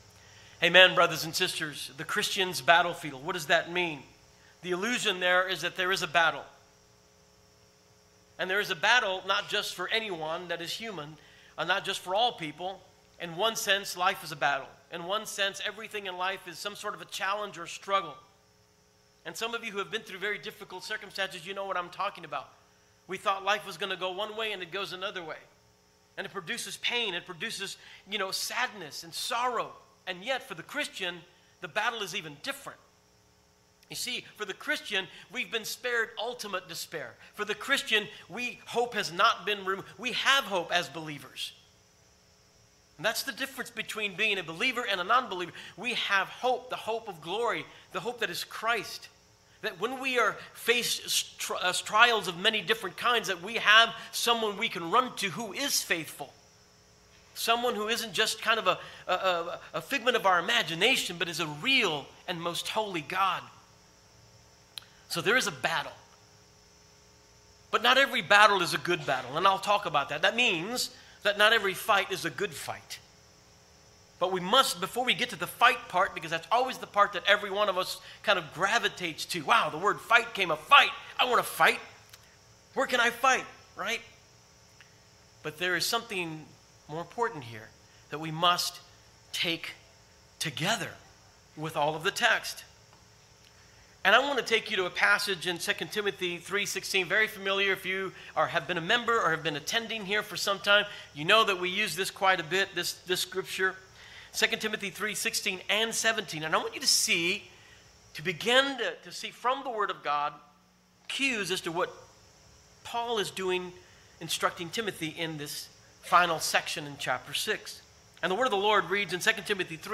Sermons | Christ Redeemer Church